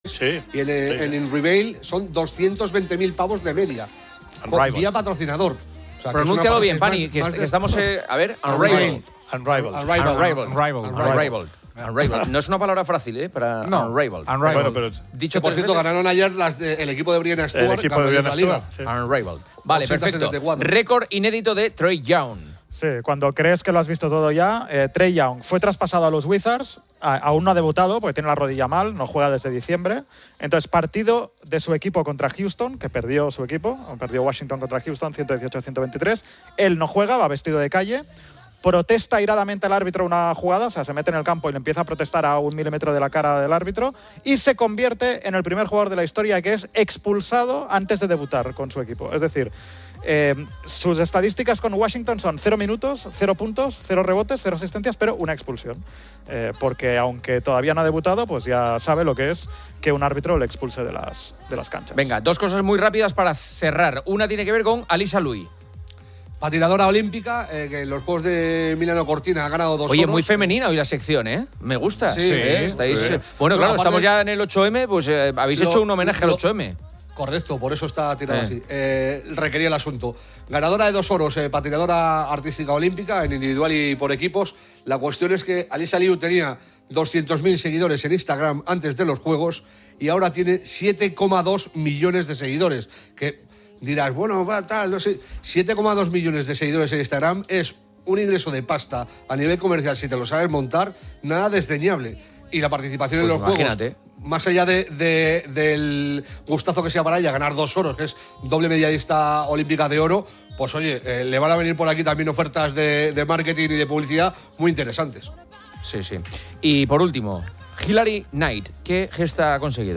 El programa entrevista a Cristina López Schlichting, directora de “Fin de Semana”, quien habla sobre su herencia hispano-alemana, destacando la franqueza y la búsqueda de la verdad alemanas en contraste con la espontaneidad y creatividad españolas. Reflexiona sobre la persistencia de machismos en la sociedad española y la precaria situación de muchas mujeres a nivel global, que aún sufren amputaciones genitales o se les prohíbe la escuela.